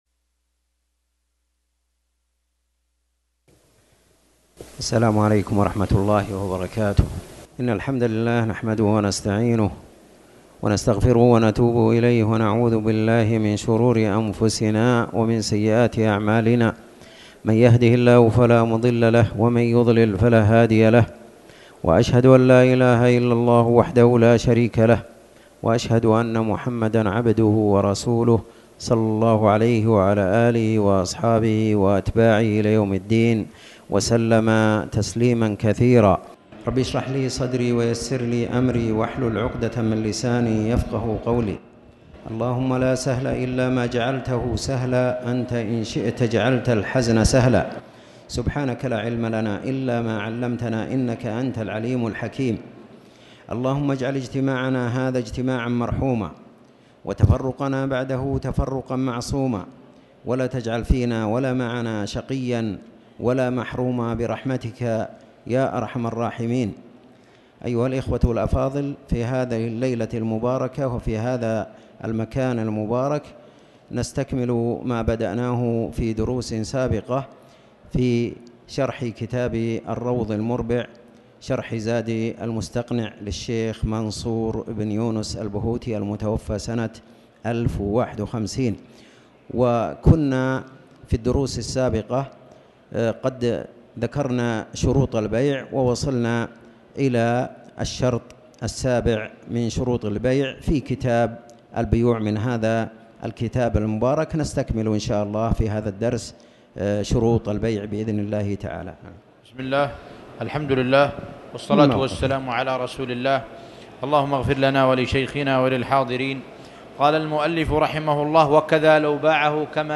تاريخ النشر ٢٠ جمادى الأولى ١٤٣٩ هـ المكان: المسجد الحرام الشيخ